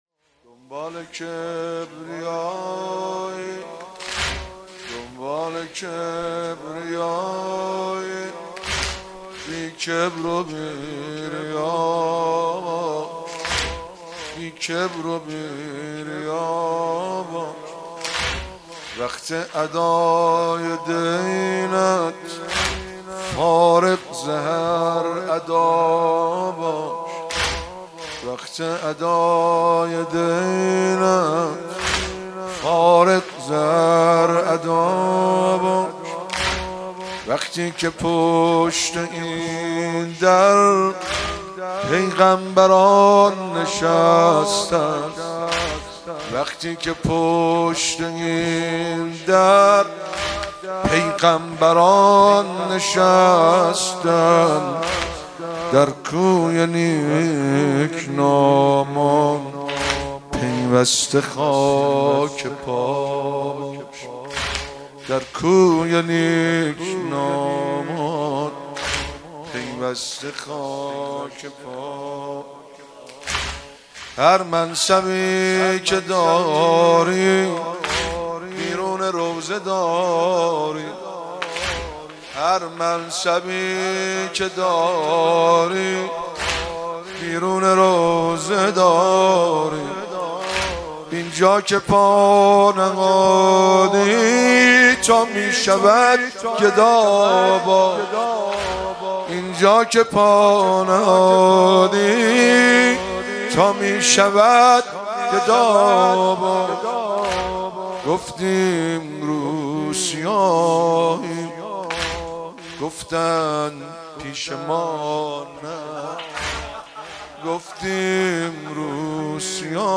مراسم شهادت حضرت رقیه (س)- شهریور 1401